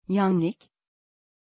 Aussprache von Yannik
pronunciation-yannik-tr.mp3